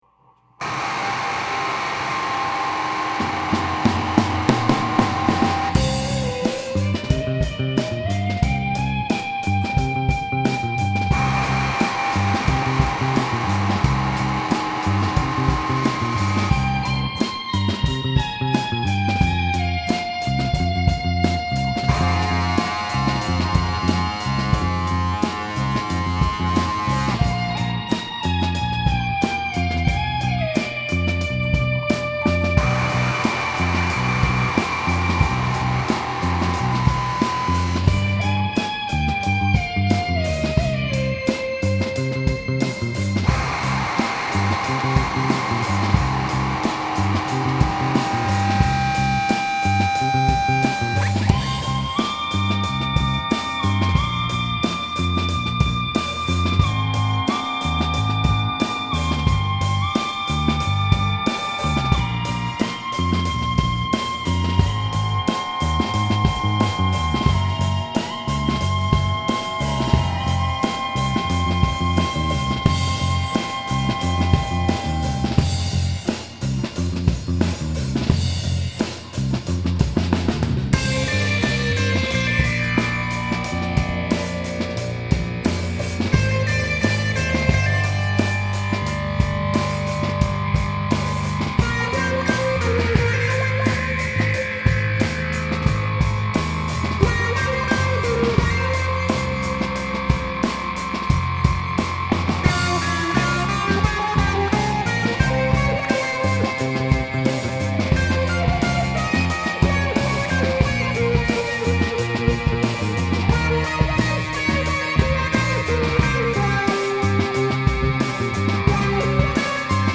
psychedelic interpretation
the psychedelic instrumentalists